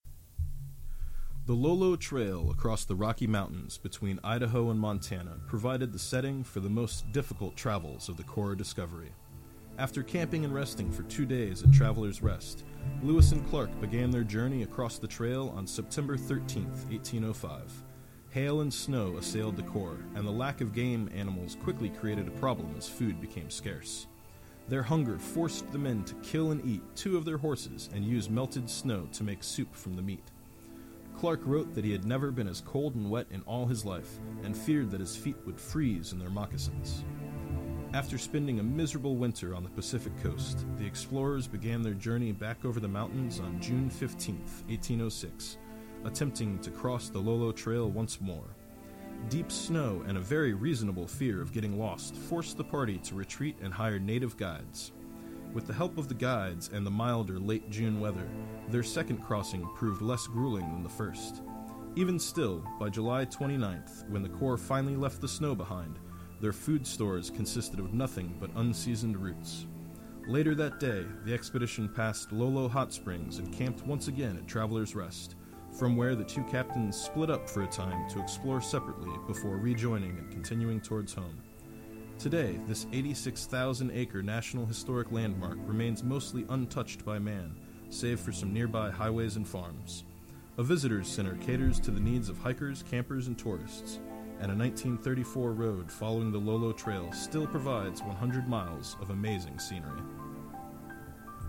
Researched, written, and narrated